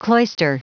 Prononciation du mot cloister en anglais (fichier audio)
Prononciation du mot : cloister
cloister.wav